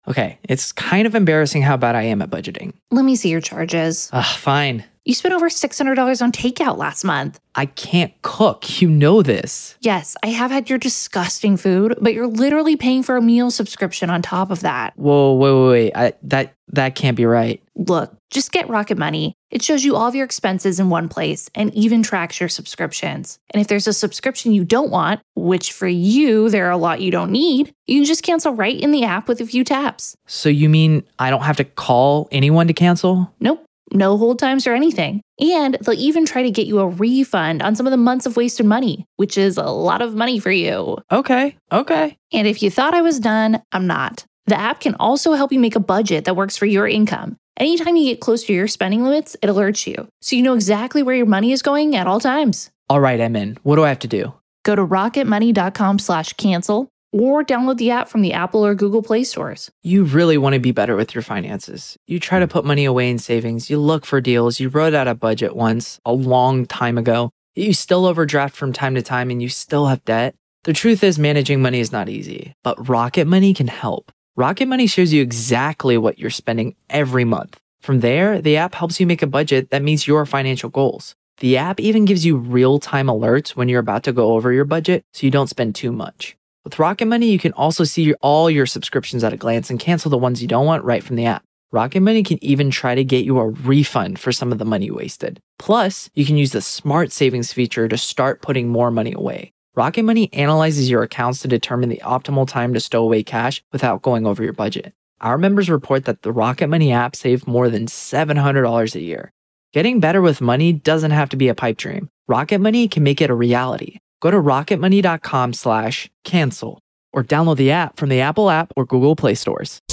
In this deep-dive monologue, we cut through the noise, the family drama, the online chaos, and the TikTok meltdowns to focus on what the evidence actually says.